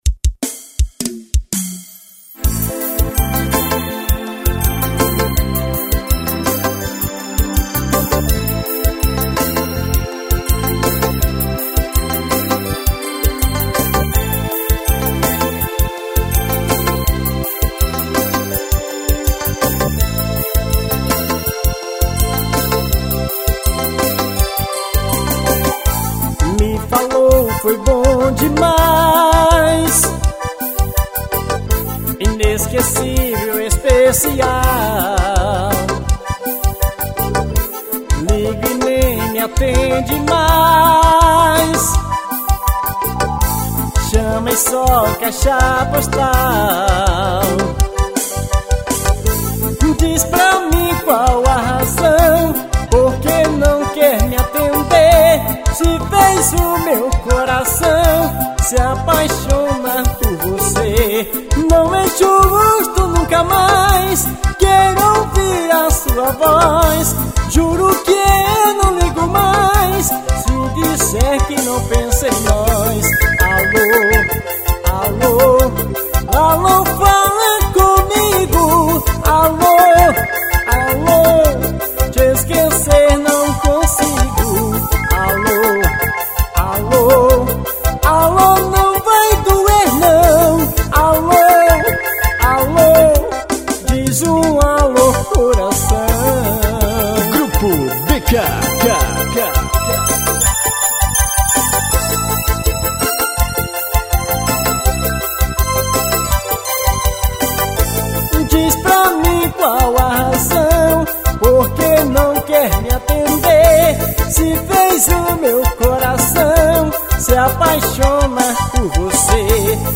Composição: forró.